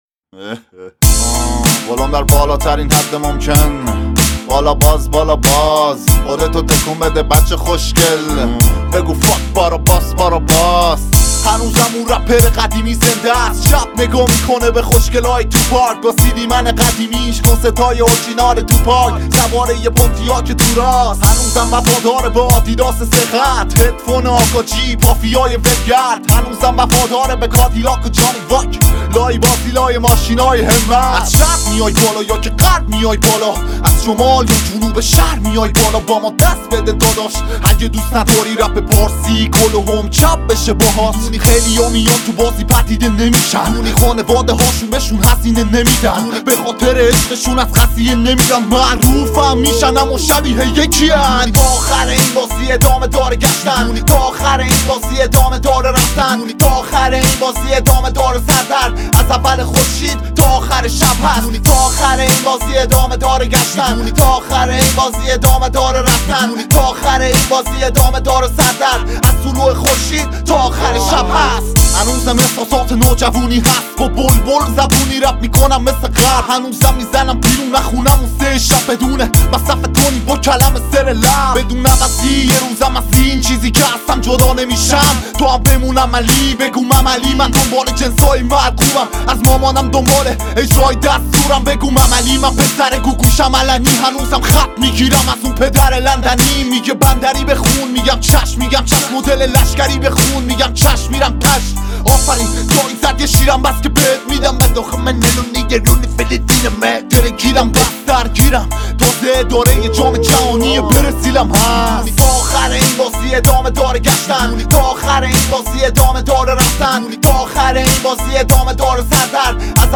جدیدترین موزیک‌های هیپ‌هاپ را اینجا بشنوید!